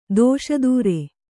♪ dōṣa dūre